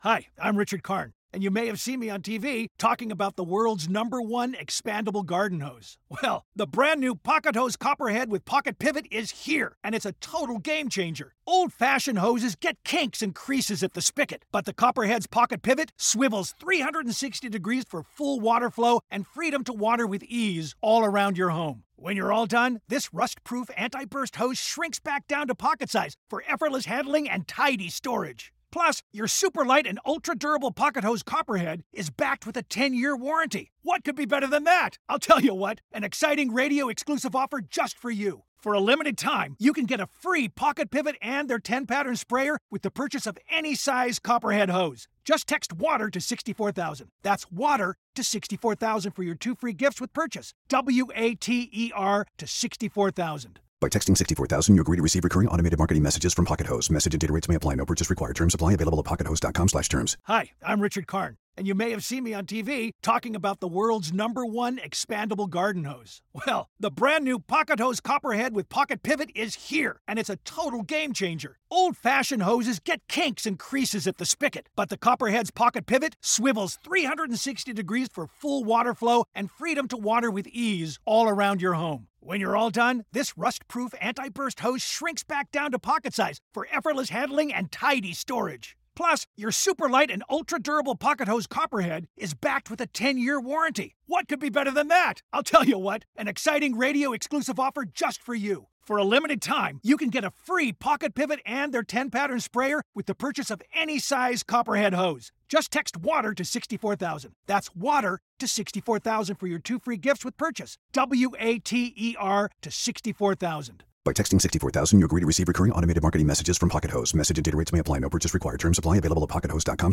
Our latest guests on Soundtracking are director John M Chu and composer and lyricist Stephen Schwarz, who join me to discuss John's take on Stephen's classic stage show, Wicked.